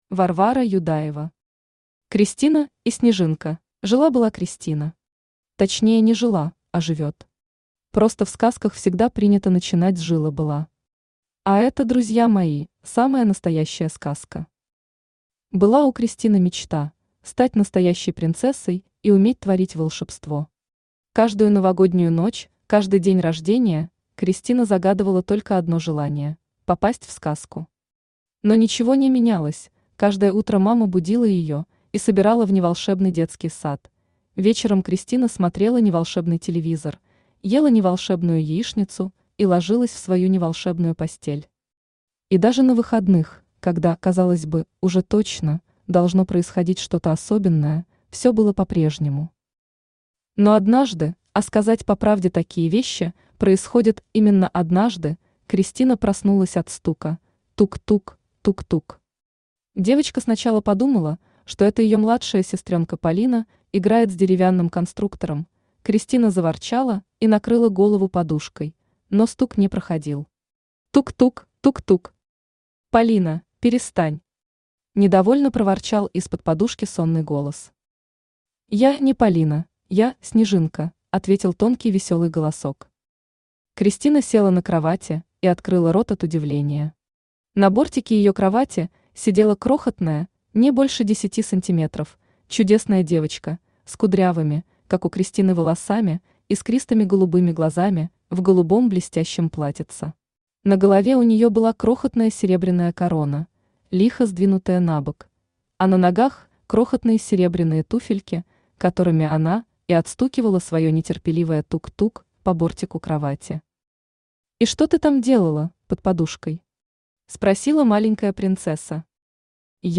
Аудиокнига Кристина и Снежинка | Библиотека аудиокниг
Aудиокнига Кристина и Снежинка Автор Варвара Александровна Юдаева Читает аудиокнигу Авточтец ЛитРес.